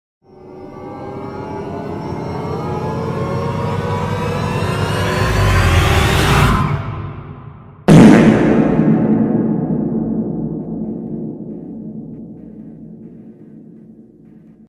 Dramatic Fart Sound Button - Free Download & Play
Memes Soundboard2,022 views